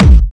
Kick 9.wav